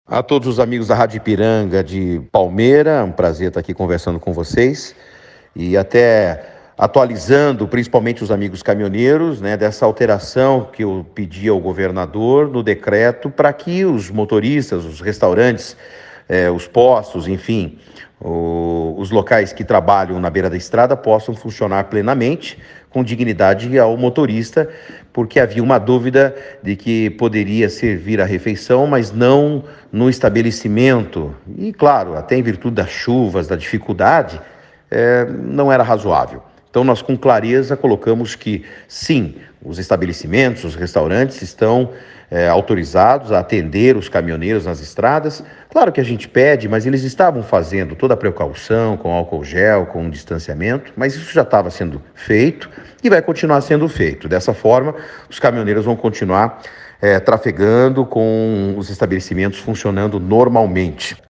O secretário de Estado da Infraestrutura e Logística Sandro Alex, falou para a reportagem da Rádio Ipiranga sobre a autorização do funcionamento de restaurantes localizados às margens das rodovias no Paraná.